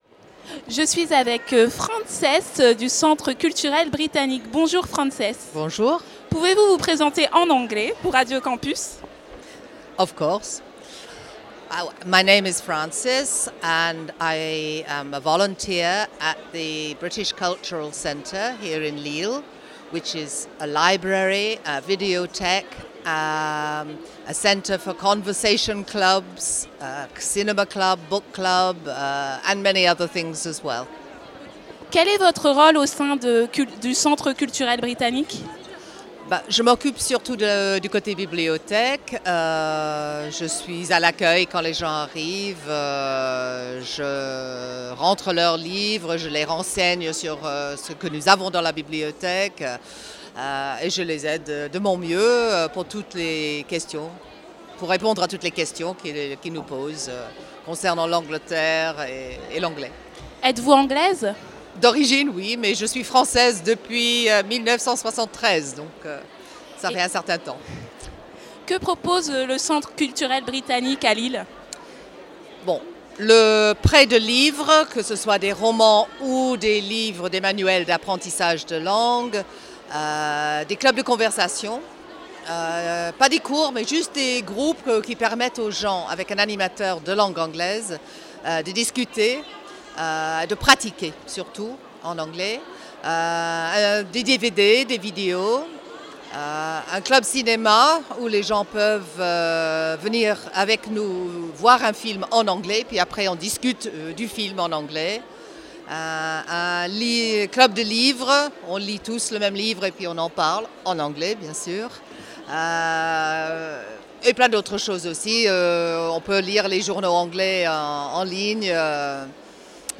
à la CCI de Lille
Interviews réalisées pour Radio Campus